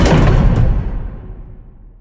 dockingairlock.ogg